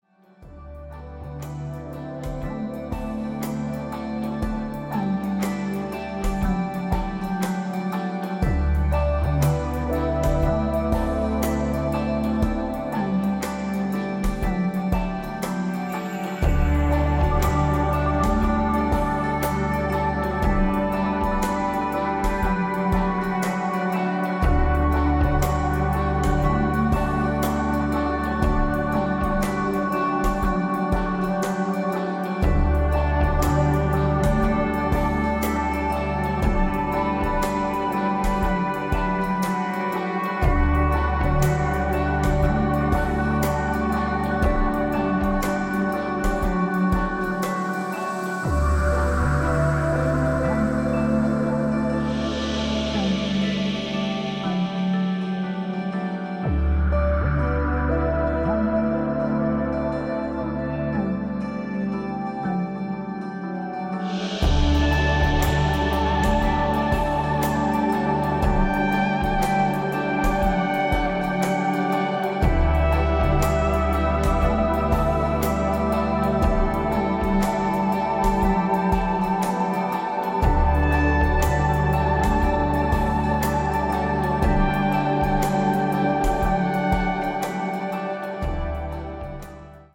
ノルウェーのアンビエント系鬼才ギタリスト、2017年最新作！
エレクトロニクス、ギターによるドリーミーなファンタジックな音世界が展開。